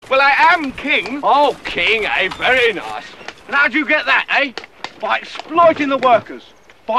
Tags: movie quote trivia pub quiz game time trivia